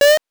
jump_3.wav